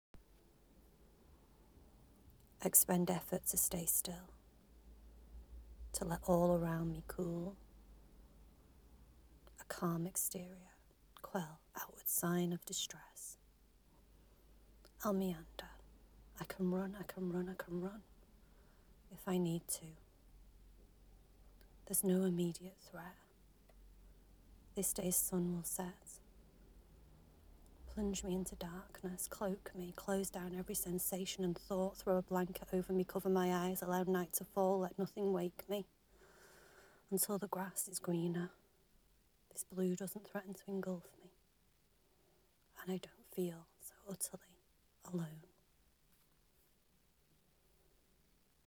Love the accent.
And how you read too, you read like a professional spoken word artist, do you do them there , iv heard of so many spoken word events in the uk!
There is an internal music to the way it unfolds.
I felt a bit guilty leaving the emotion in the audio rather than re-recording it but as I mentioned below there is an element of catching a moment sometimes.